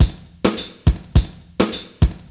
• 104 Bpm Fresh Drum Loop Sample C Key.wav
Free drum beat - kick tuned to the C note. Loudest frequency: 3415Hz
104-bpm-fresh-drum-loop-sample-c-key-sgo.wav